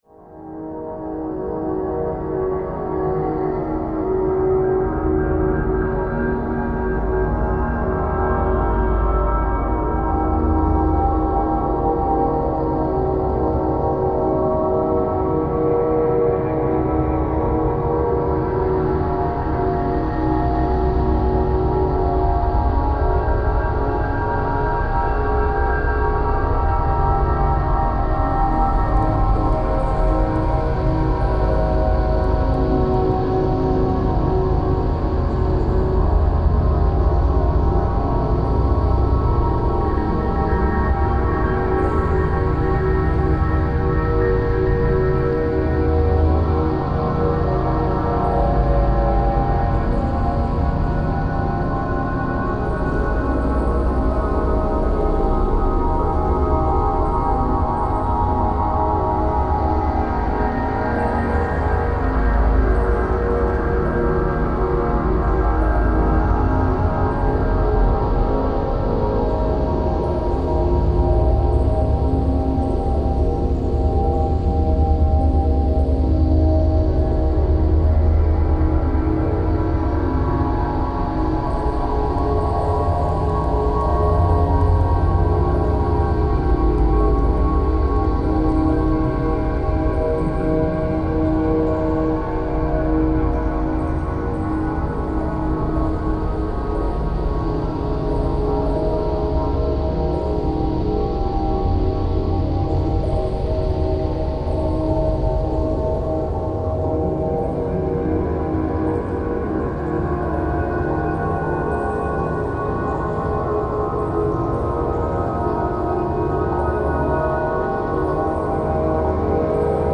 Sa Pa, Vietnam water sounds reimagined